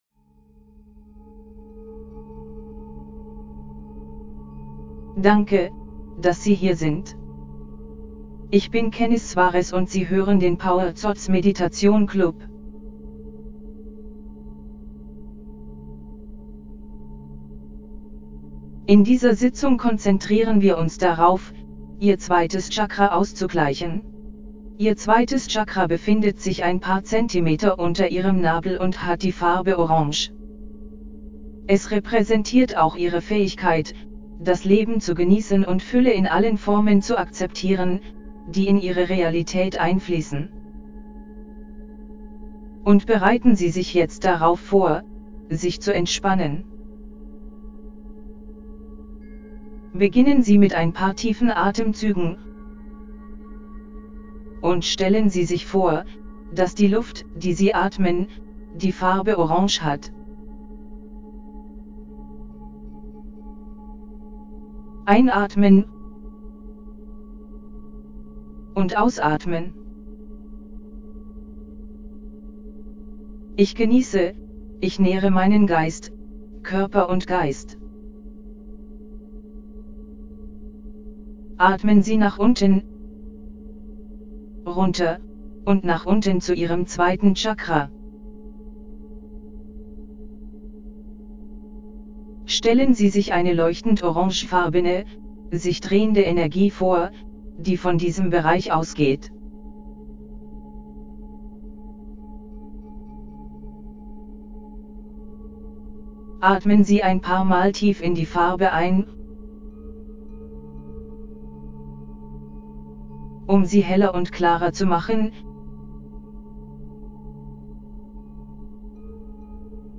Aktivieren Sie den Qi-Fluss Ihres Sakralchakras. Um die Heilung Ihrer Sakralchakra-Meditation zu verbessern, haben wir das Sakralchakra Solfeggio 417 Hz verwendet.
2ActivatingQiFlowOfSacralChakraMeditationDE.mp3